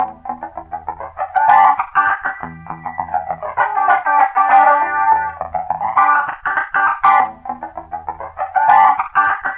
Loops guitares rythmique- 100bpm 3
Guitare rythmique 51